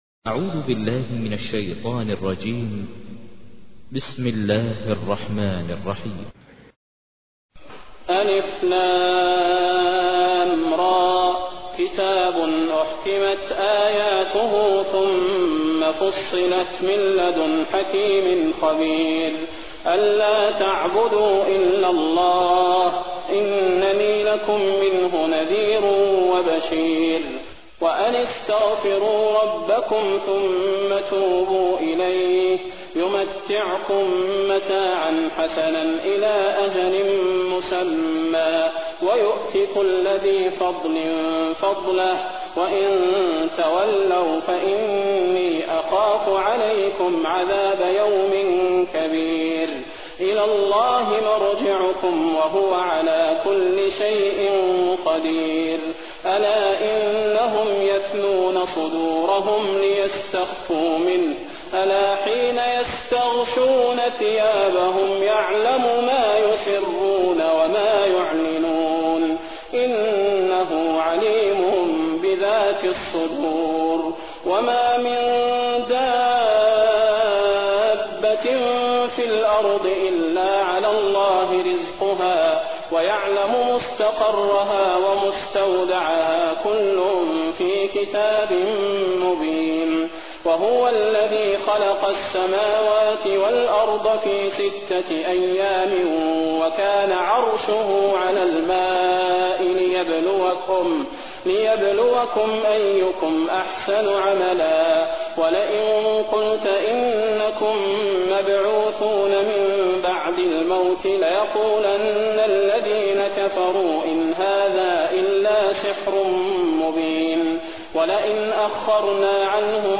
تحميل : 11. سورة هود / القارئ ماهر المعيقلي / القرآن الكريم / موقع يا حسين